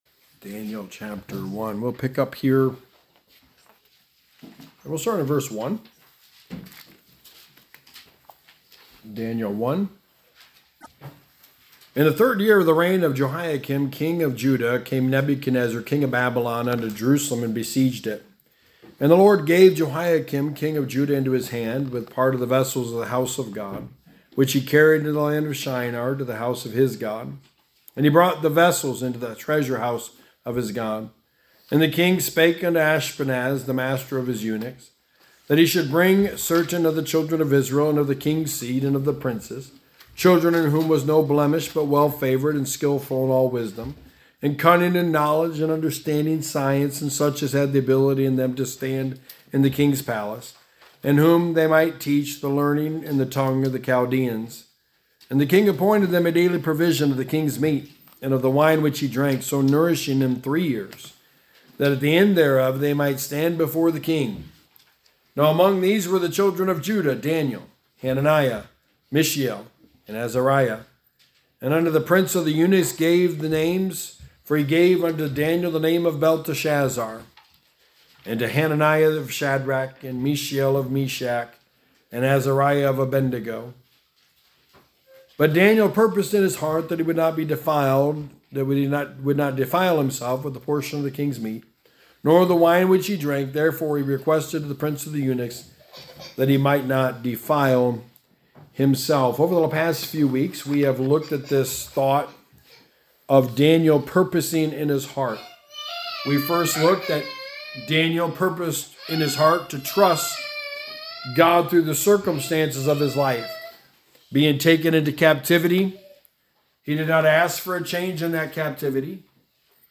Walking With Daniel – A study of the life of Daniel / Sermon #6: A Purposed Life – Daring to Be Different
Service Type: Sunday Morning